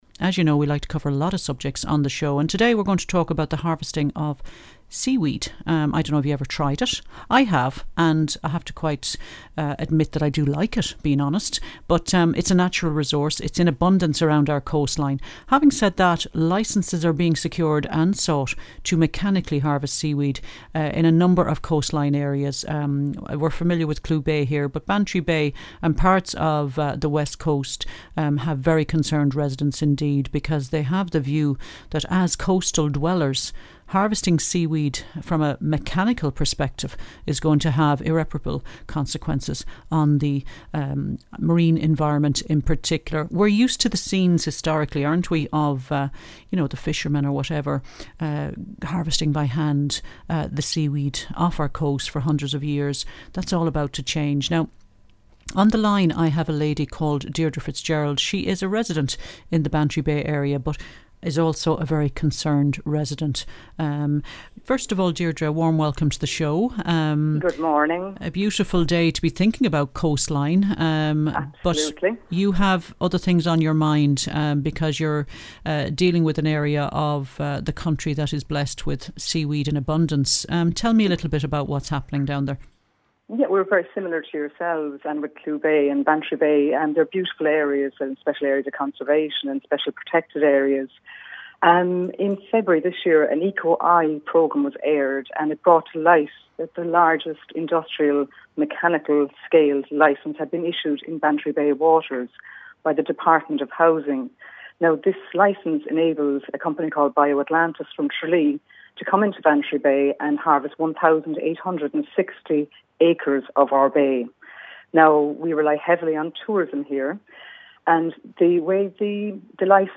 Local radio in Youghal, East Cork.